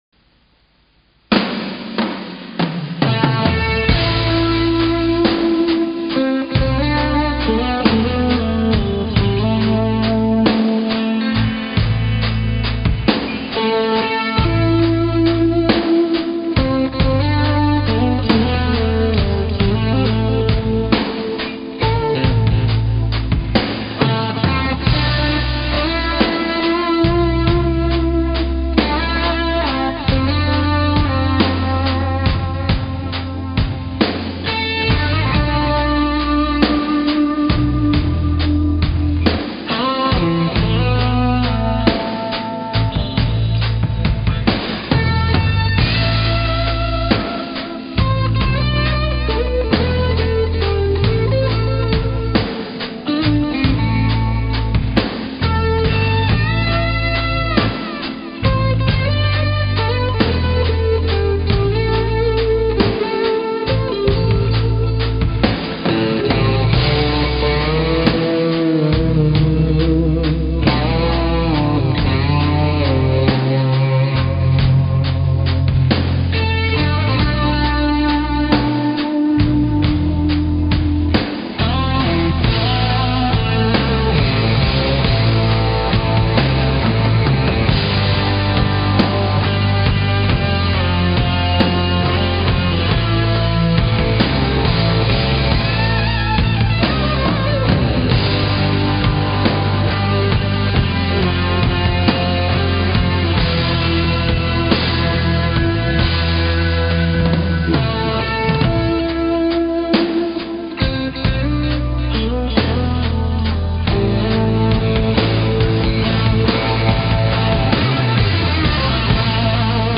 0183-吉他名曲甲鱼头.mp3